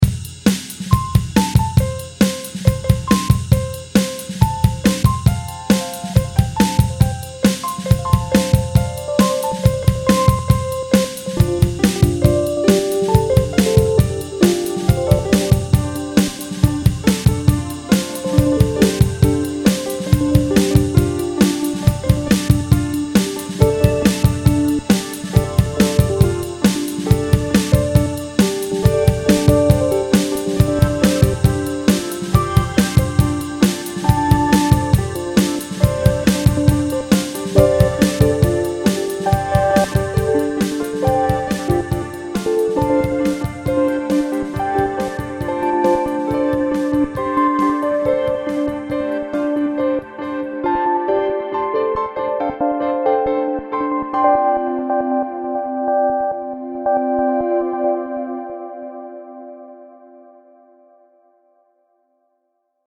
In Ableton Live I added a beat, then I recorded midi of me banging to it in different variations.
The scale plugin was fixed so that notes could not clash with each other. Then I recorded the output of that midi data into a few tracks with softsynths. It sounds pretty art musicy, but still good.
testing-the-generative-melody-idea.mp3